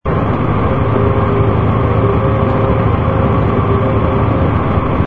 engine_br_fighter_loop.wav